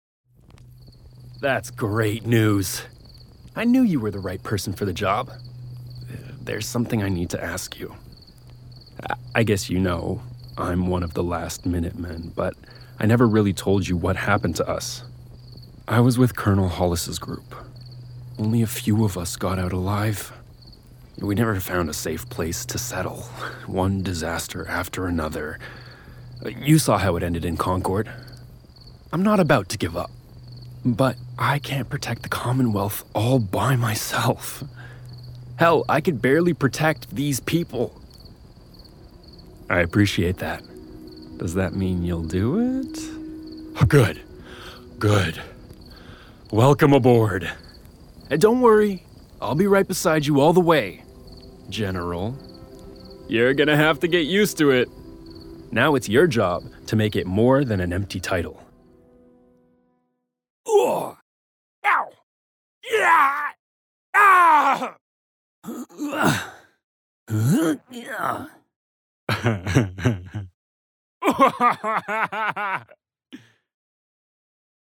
Démo jeu vidéo - ANG